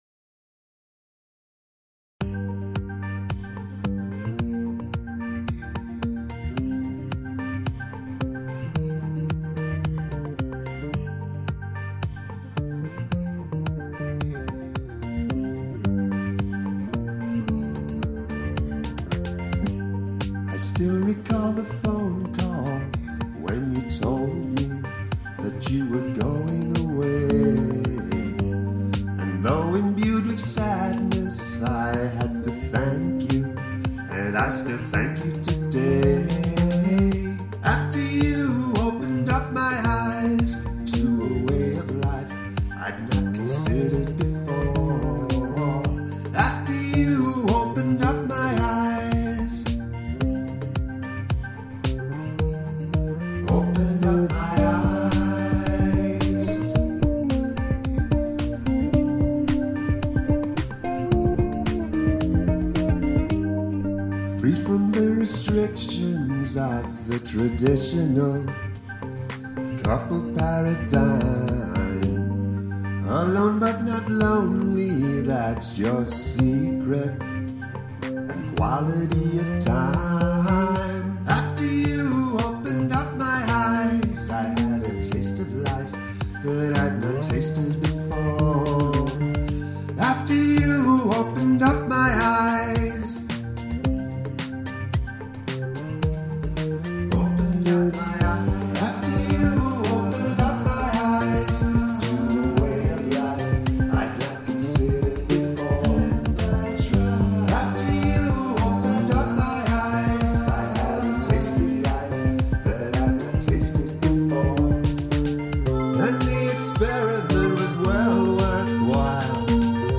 Counter Melody
Sounds a bit more rushed than the last one.